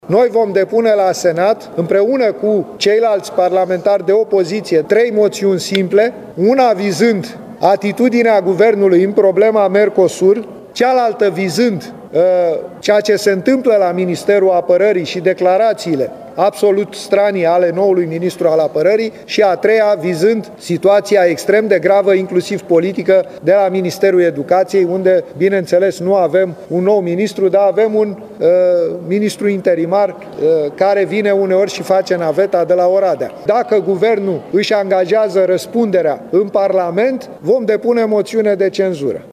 Parlamentarul AUR, Petrișor Peiu: Noi vom depune la Senat trei moțiuni simple